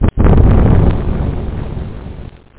DEEPEXPL.mp3